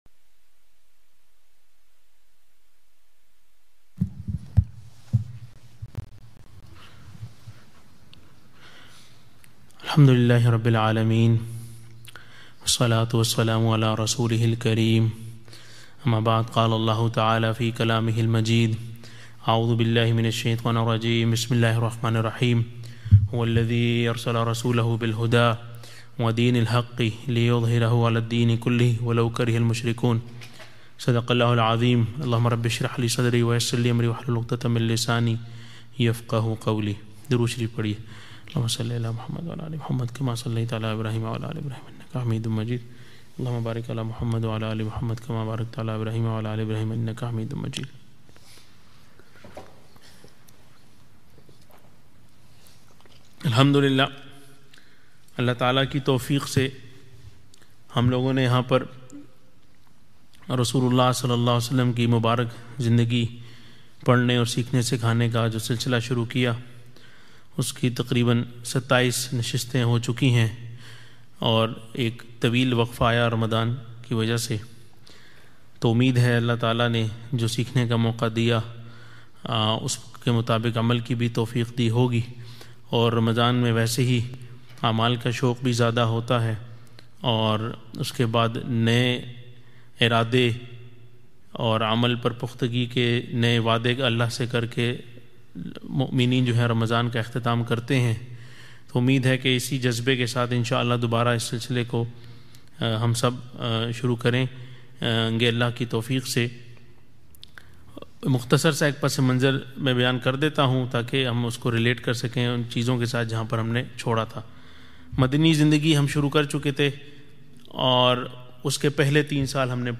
Urdu Seerah Dars